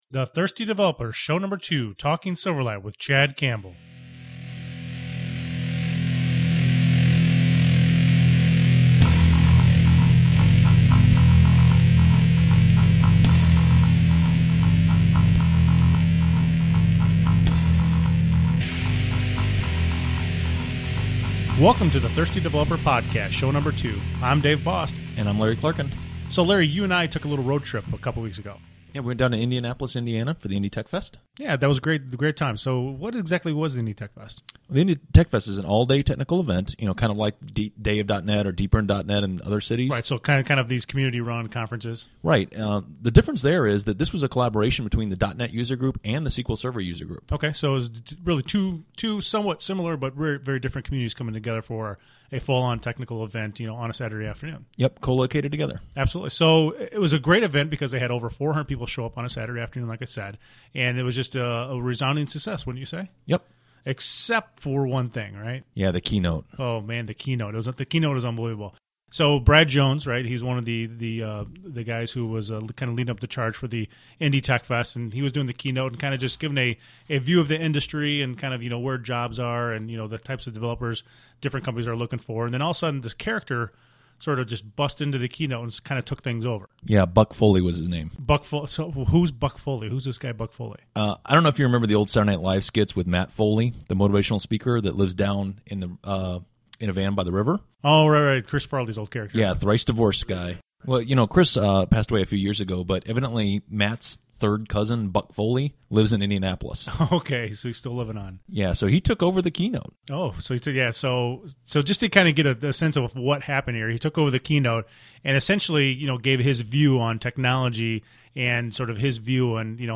We caught up with him at the speaker’s room and asked him to talk about his technique for using Silverlight 1.1.